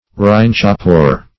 Rhynchophore \Rhyn"cho*phore\, n.
rhynchophore.mp3